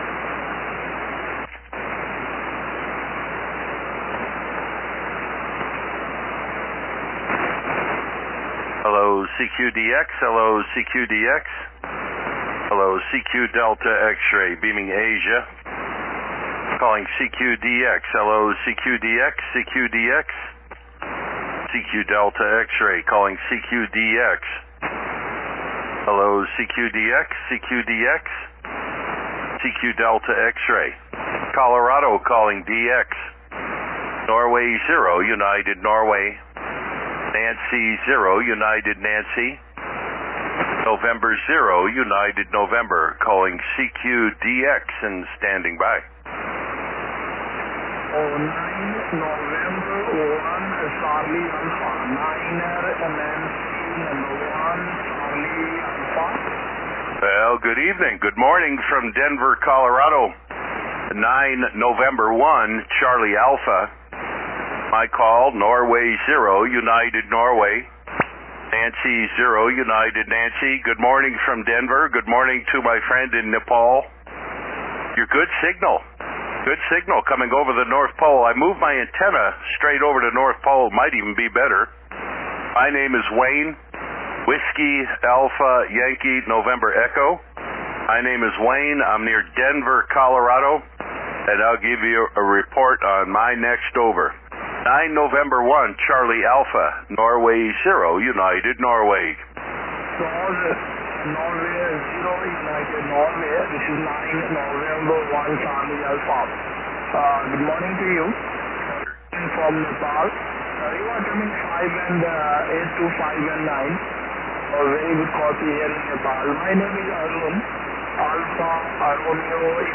I read news 3Y0K Bouvet may (or may not) be on the air so I figured what the heck, I’ll point my antenna over Asia this morning and call CQ DX on 20M, 14.195. Maybe draw Bouvet out on the Long Path if they’re indeed playing radio?